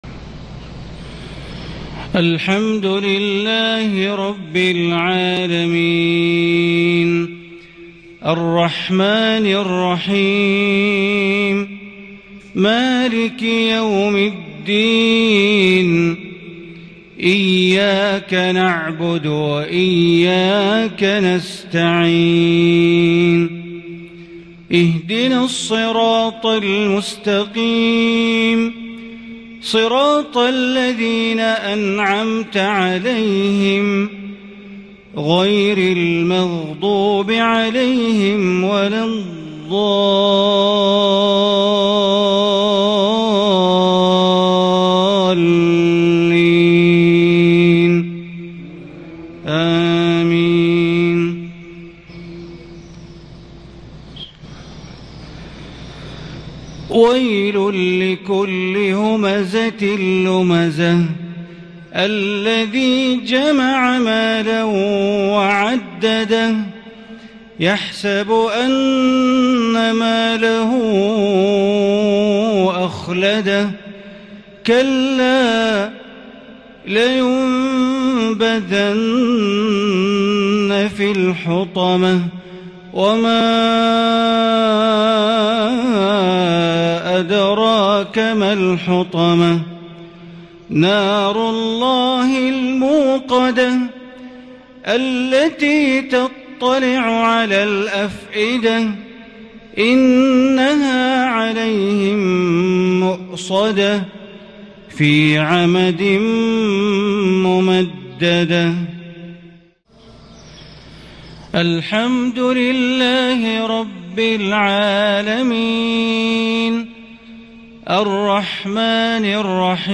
صلاة المغرب من سورتي الهمزة والفيل ١-٥-١٤٤٢هـ > 1442 هـ > الفروض - تلاوات بندر بليلة